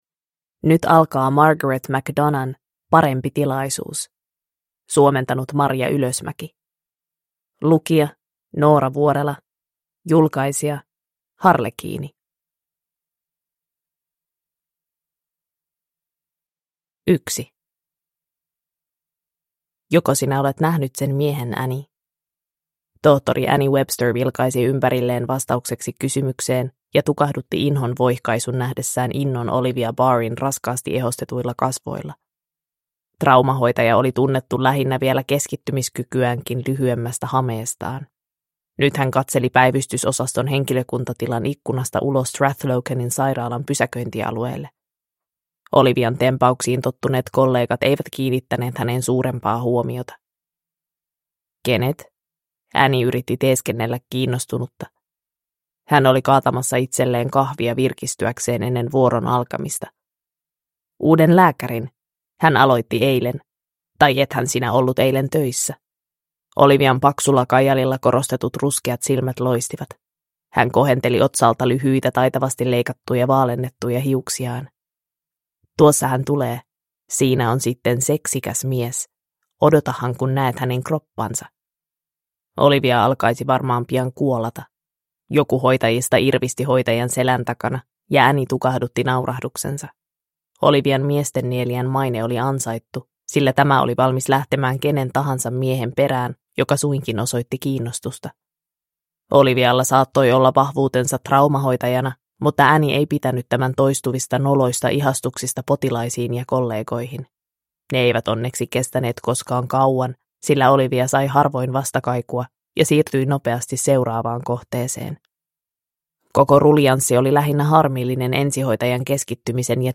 Parempi tilaisuus (ljudbok) av Margaret McDonagh